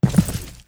FallImpact_Concrete 02.wav